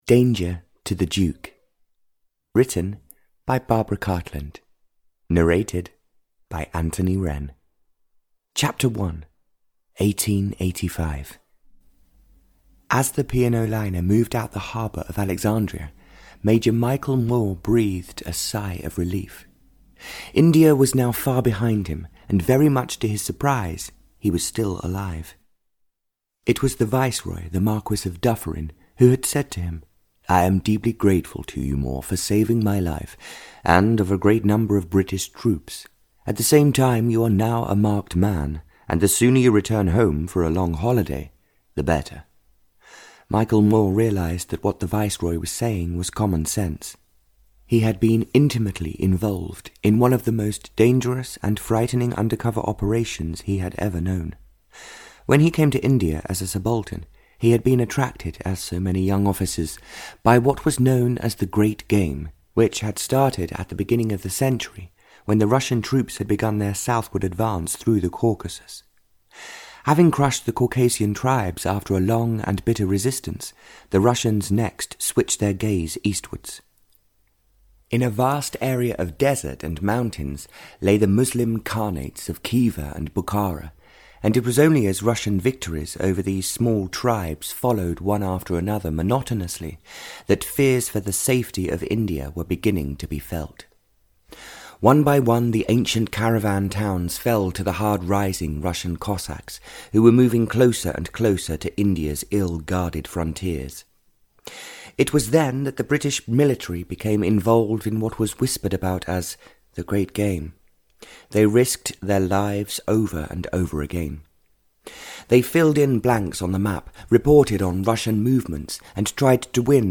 Audio knihaDanger to the Duke (Barbara Cartland’s Pink Collection 43) (EN)
Ukázka z knihy